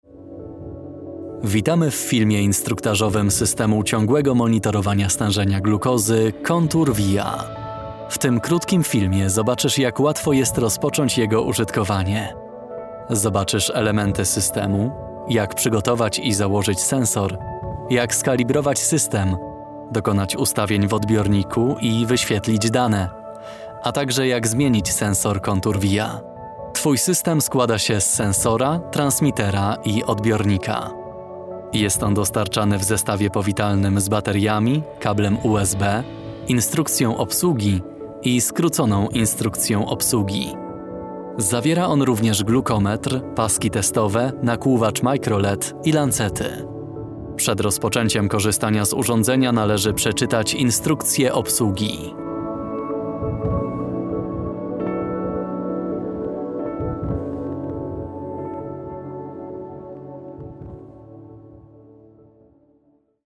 Male 20-30 lat
Young and pleasant voiceover voice.
Film instruktażowy